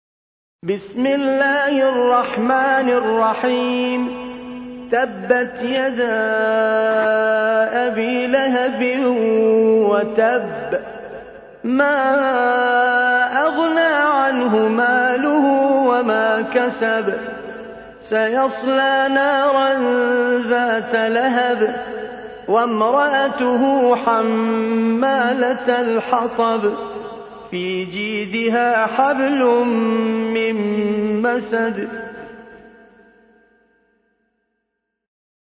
سورة المسد / القارئ